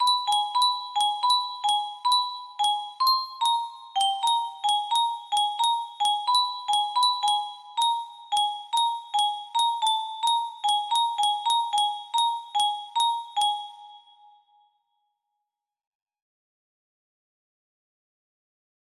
kindergarten music box melody